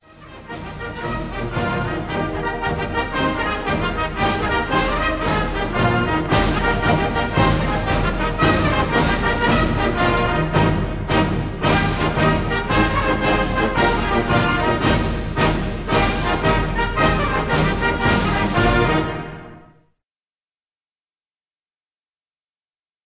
the regimental march